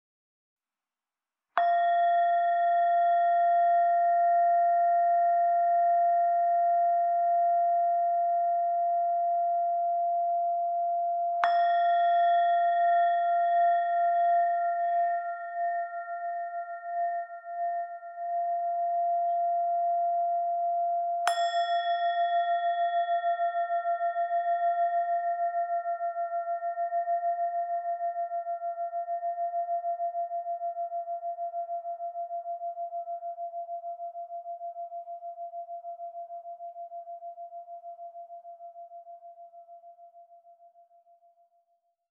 When softly tapped or rubbed, Sonic Energy singing bowls release a fascinating, multi-layered, and colorful sound that resonates deeply within the soul. Over a rich fundamental tone, entire waterfalls of singing overtones emerge to float freely in space and unfurl inside the body. Once the sound starts vibrating, it won't stop; even a minute later, a soft reverberation can still be felt.
With a bellied design created from additional hammering, these bowls generate a sustained deep tone that will fill a room with ambient sounds.